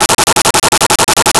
Arcade Movement 06.wav